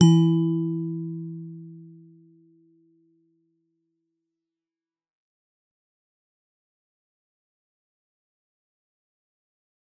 G_Musicbox-E3-f.wav